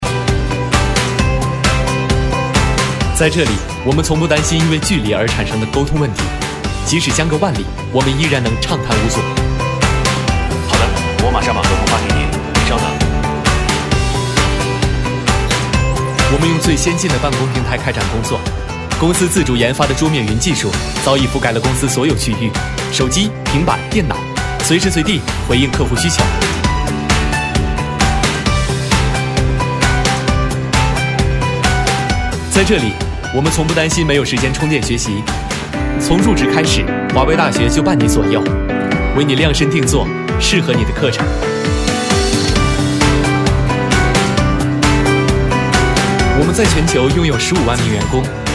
特点：年轻自然 素人 走心旁白
29男-广告-第一人称介绍风格.mp3